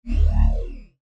На этой странице собраны разнообразные звуки лазеров — от тонких высокочастотных писков до мощных энергетических залпов.
Звук выдвигающейся лазерной установки